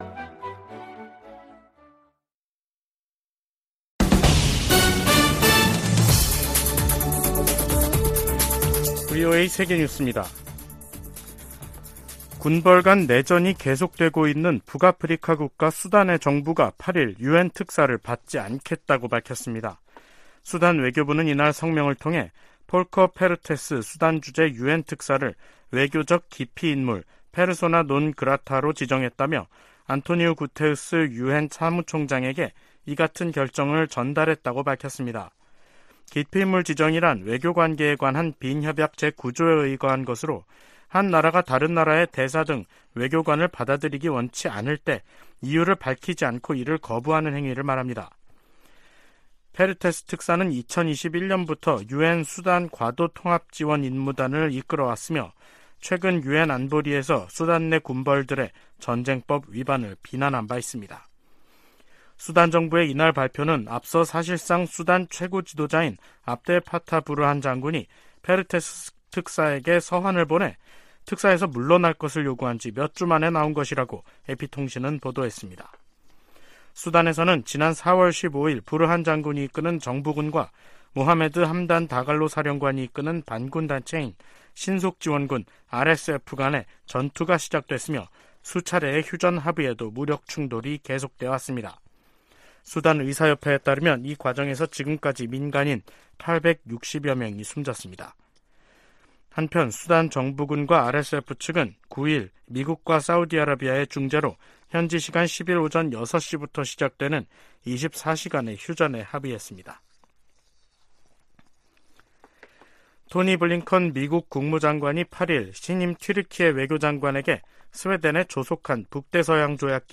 VOA 한국어 간판 뉴스 프로그램 '뉴스 투데이', 2023년 6월 9일 3부 방송입니다. 미 국무부가 한국의 새 국가안보전략을 환영한다고 밝혔습니다. 중국과 북한의 핵무력 증강에 대응해 미국도 핵전력을 현대화하고 있다고 국방부 고위 관리가 밝혔습니다. 북한이 군사정찰위성 발사 실패 이후 국제사회 비판 여론에 예민하게 반응하면서 주민들에겐 알리지 않고 있습니다.